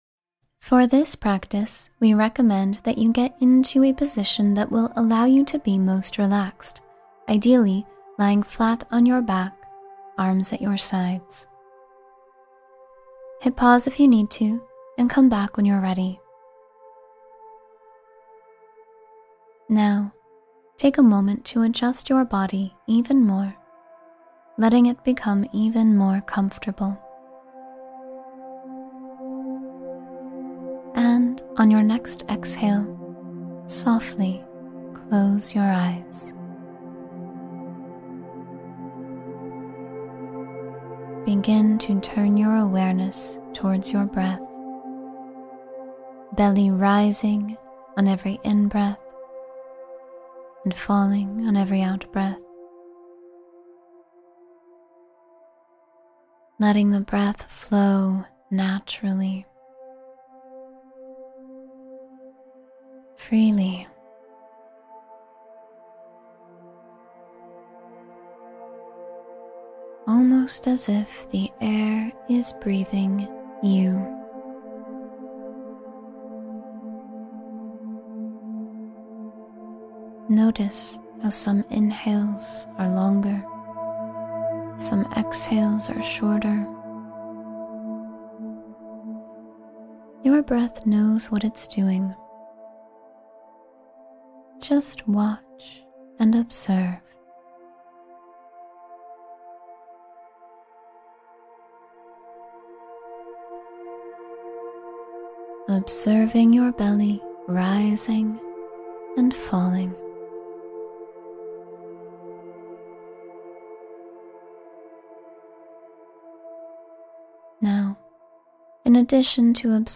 Guided Meditation for Peaceful Sleep — Relax and Let Go Completely